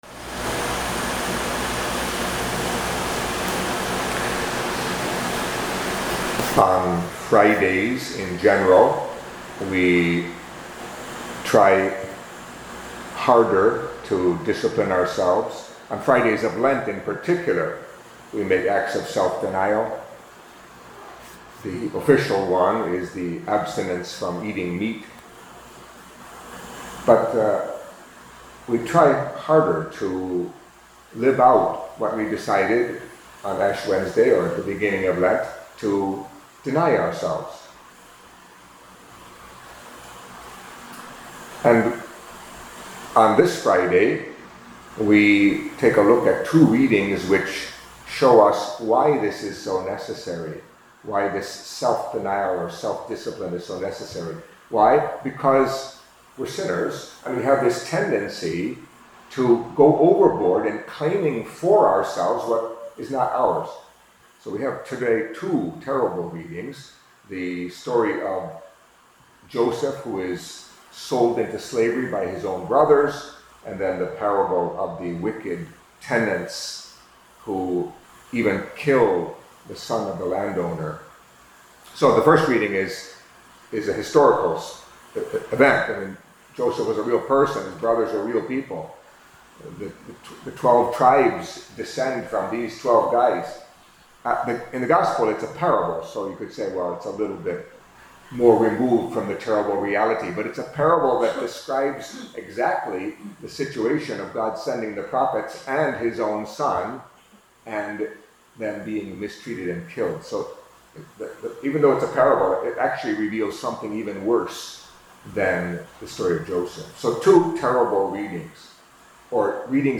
Catholic Mass homily for Friday of the Second Week of Lent